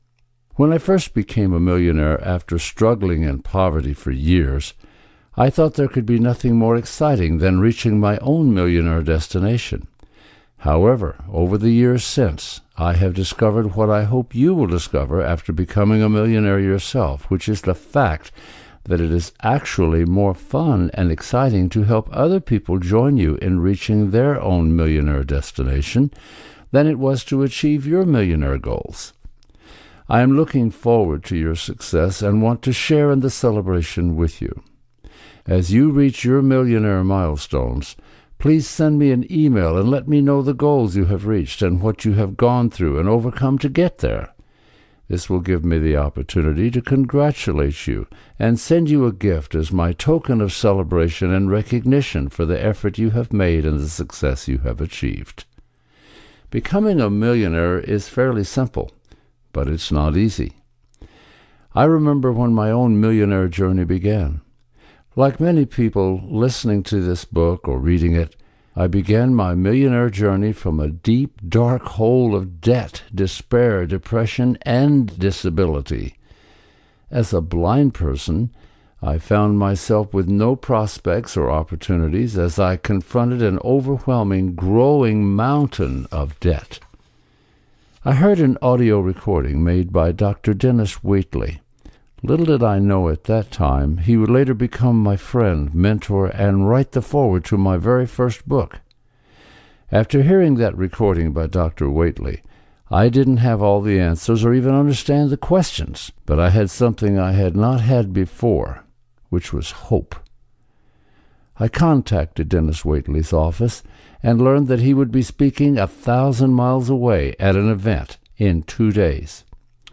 Genre: Audiobook.